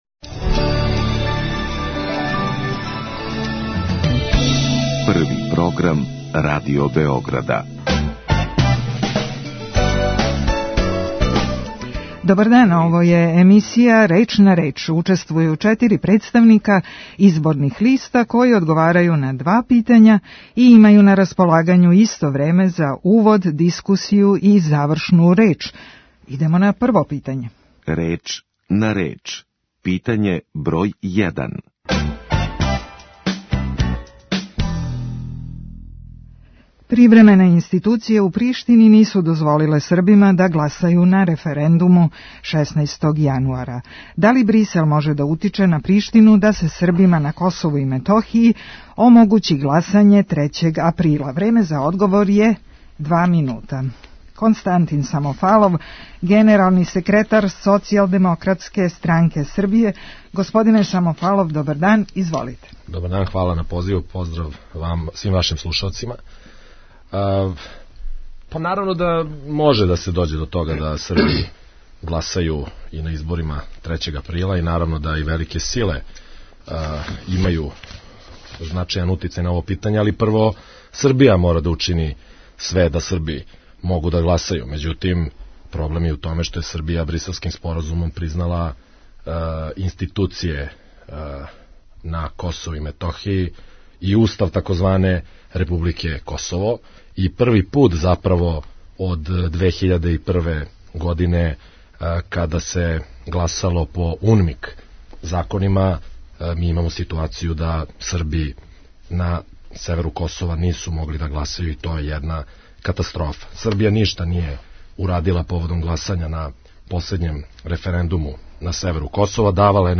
Ako Priština nema nameru da ispuni odredbe Briselskog sporazuma, pre svega u delu koji se tiče ZSO - kako Beograd treba da se postavi prema tom sporazumu i prema dijalogu u Briselu? Svakog petka od 17.05. u emisji „Reč na reč" debatuju četiri predstavnika izbornih lista koje najavljuju učešće na izborima 3.aprila. Razgovor u emisiji odvija se prema unapred određenim pravilima tako da učesnici imaju na raspolaganju isto vreme za uvod, diskusiju i završnu reč.